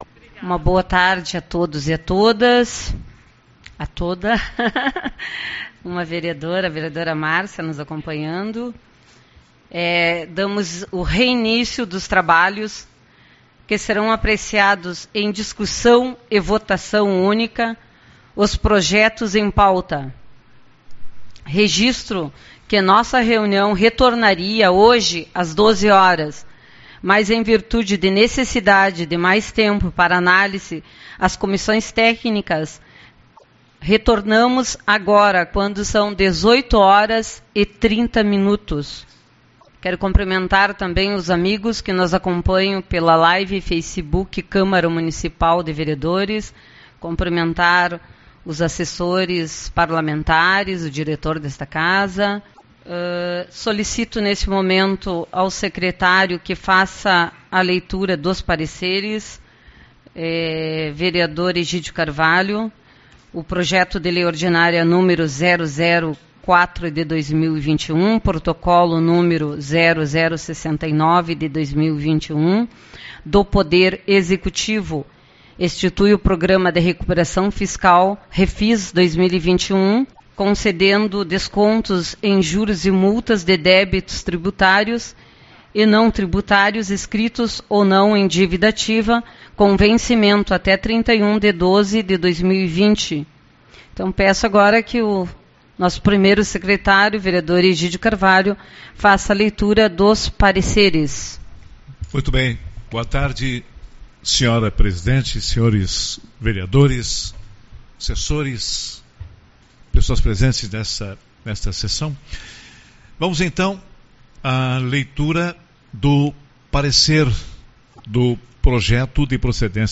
Reunião Representativa